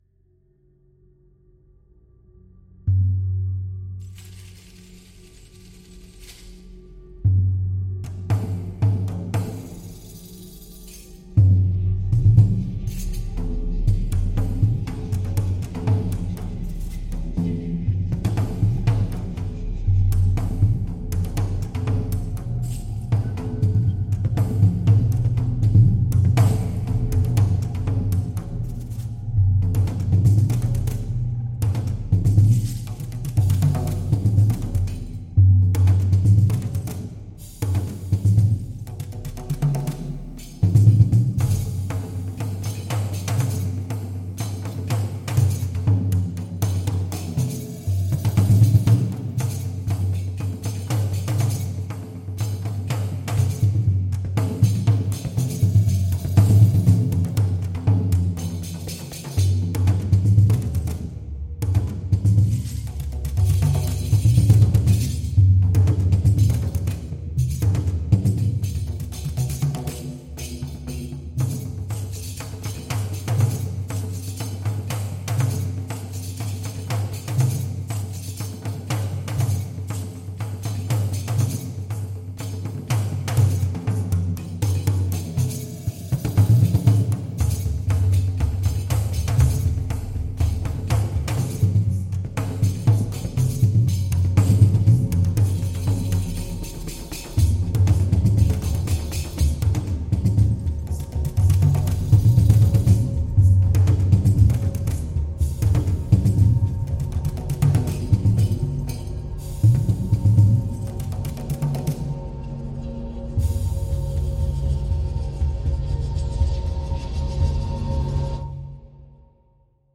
叮当声、拨浪鼓或铃声通常作为鼓的谨慎组成部分演奏，以及各种独特的头部和边缘手指和手关节。
对于我们的Riq Drum多样本库，我们捕获了一对全尺寸鼓，一个来自希腊的较大的15英寸“daf”鼓，带有金属环和轴承（实际上称为daf），以及一个来自埃及的较小的13英寸riq鼓，带有宽锡“钹”。
• 91 通过操纵原始 Riq 和 Daf 录音，维持环境纹理、有机合成器音调和氛围。